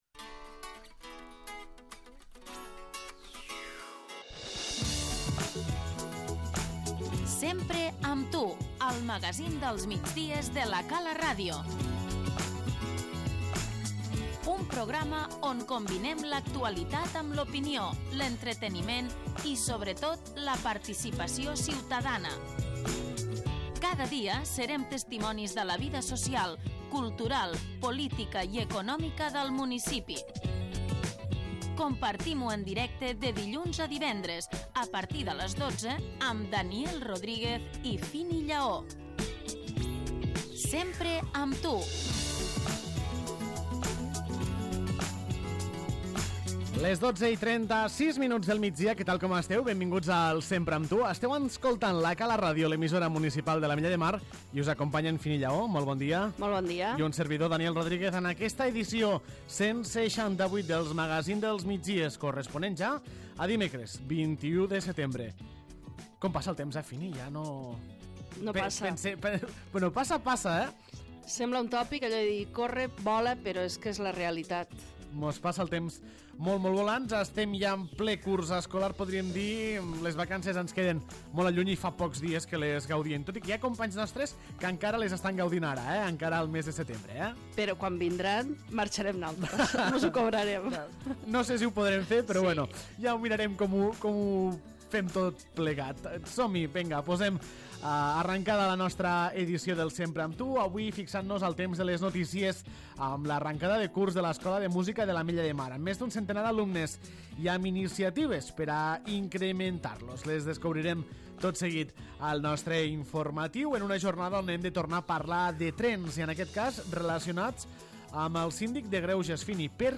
Ben trobats de nou al Sempre amb tu, el magazín dels migdies de La Cala Ràdio.